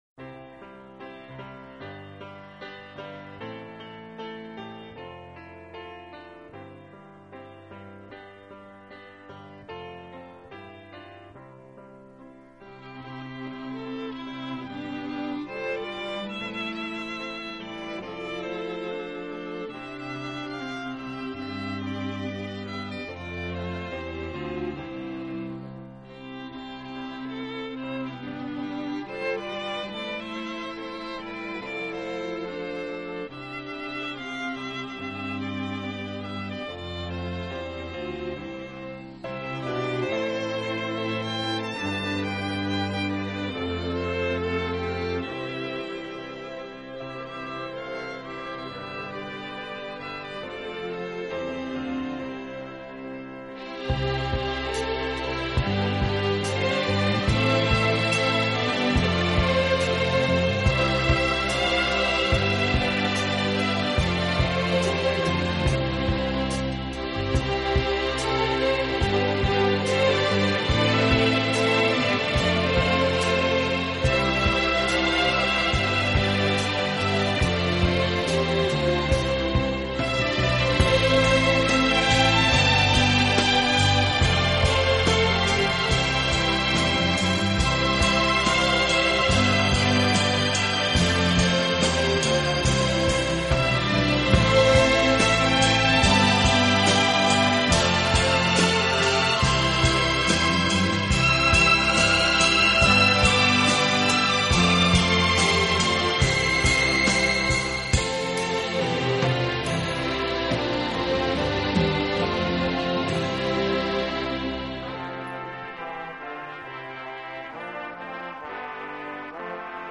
【顶级轻音乐】
Genre: Instrumental